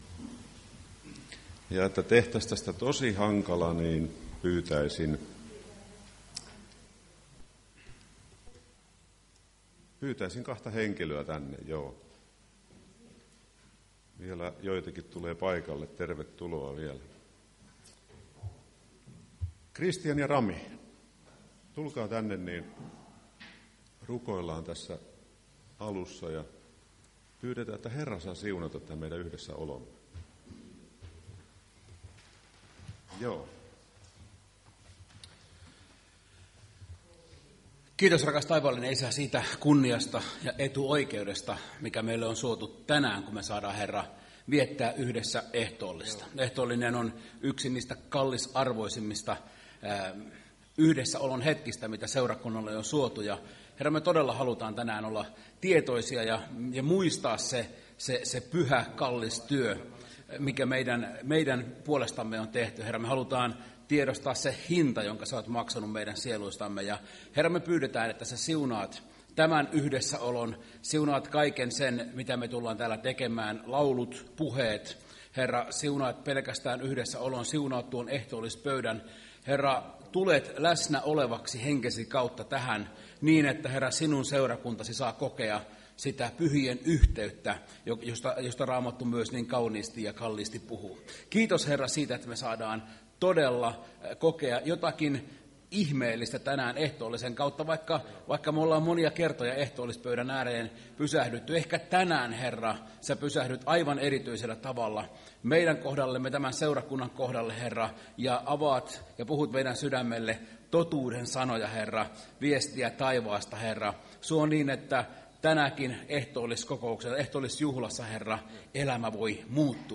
Ehtoolliskokous 6.4.2025
Sunnuntain 6.4.2025 Ehtoolliskokous, jossa puhui puhujan nimi.